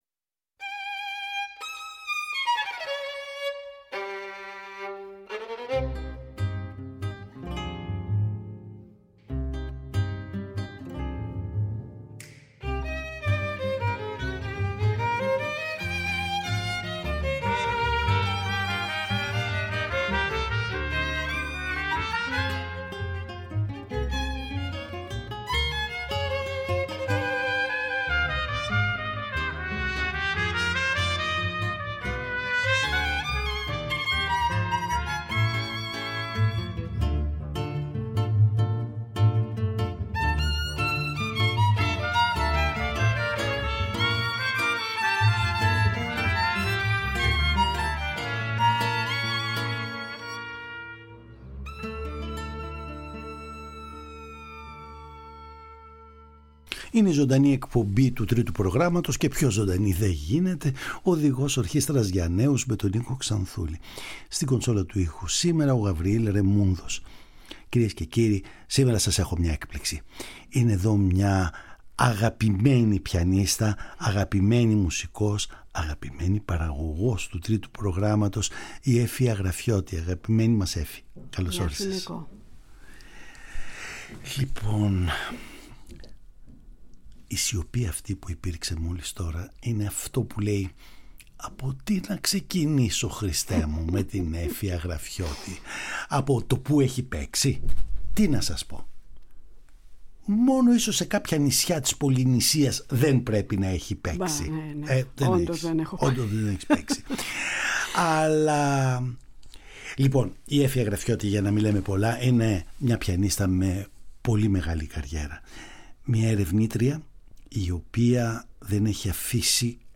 Μέσα σε δύο ώρες σκοπεύουμε να απολαύσουμε το υπέροχο παίξιμό της και τη σοφία των λόγων της.
Συνεντεύξεις Εργα για Πιανο